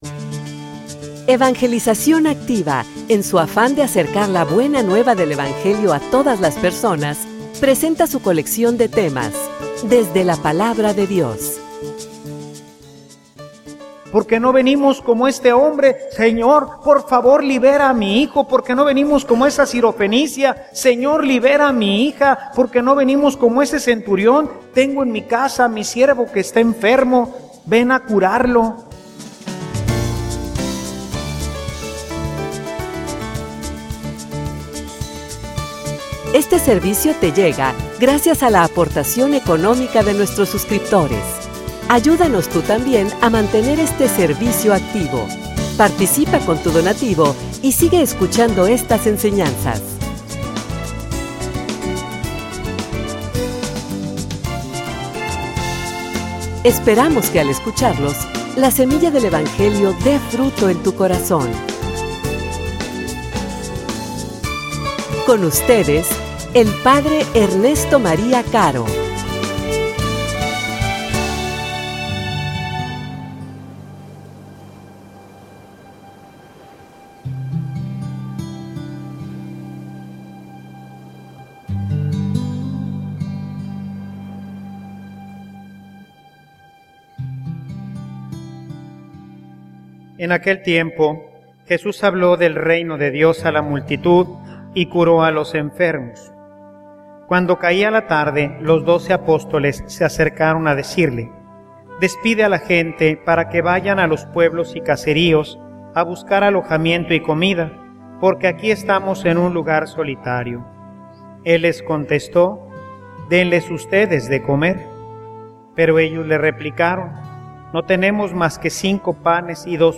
homilia_Por_que_no_lo_buscas_tu.mp3